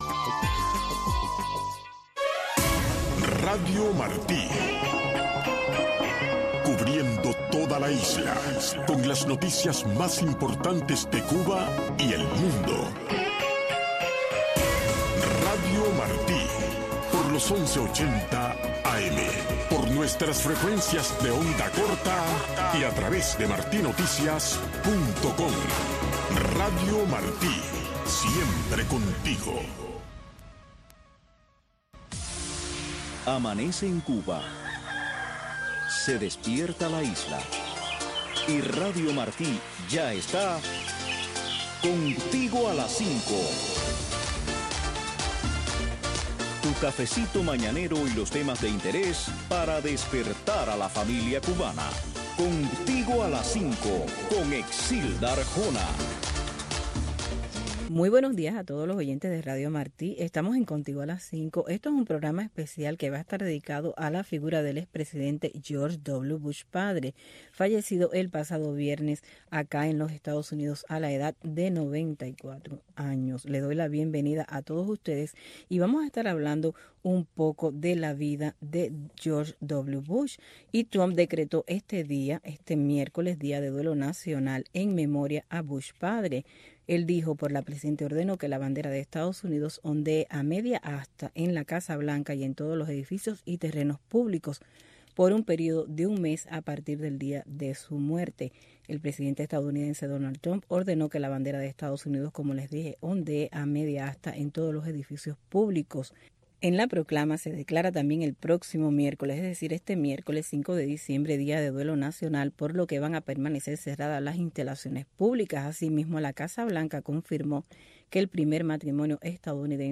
Revista informativa, cultural, noticiosa